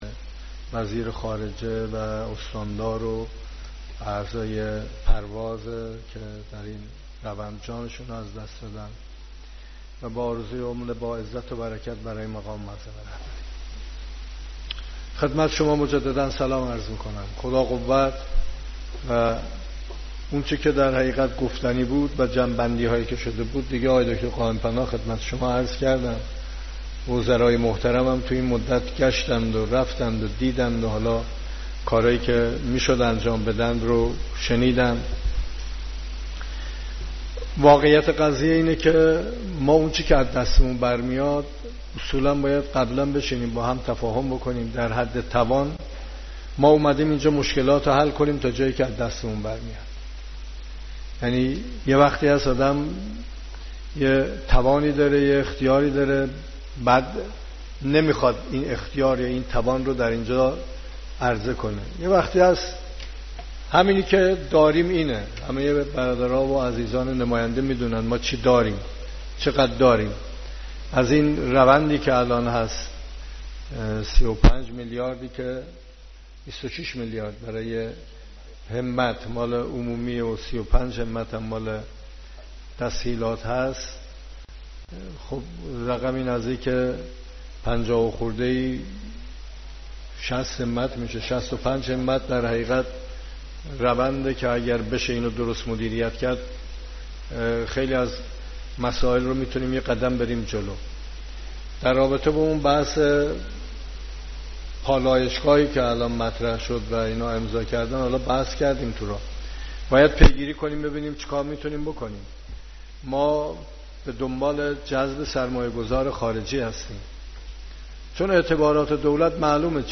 سخنان رئیس جمهور در نشست شورای اداری استان کرمانشاه